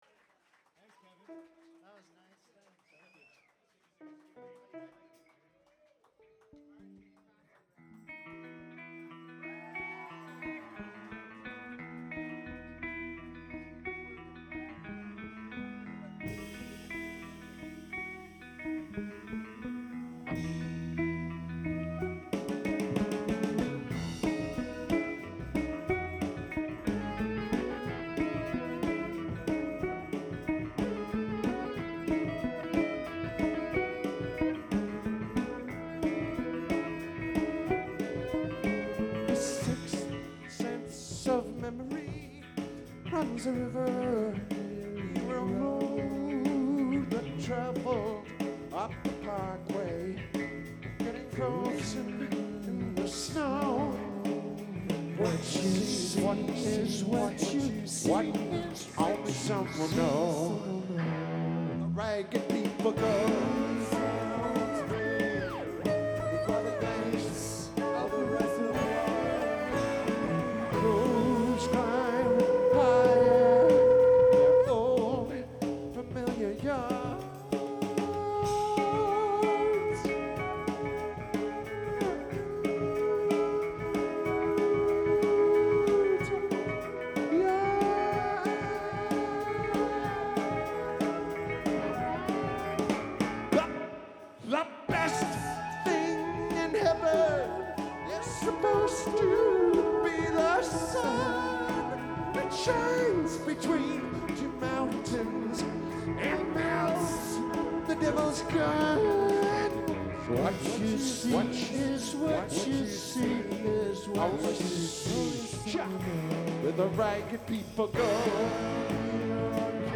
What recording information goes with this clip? The Horseshoe Tavern Toronto December 9 2016 Soundboard > Edirol R-44 > direct to SD > flac/wav/mp3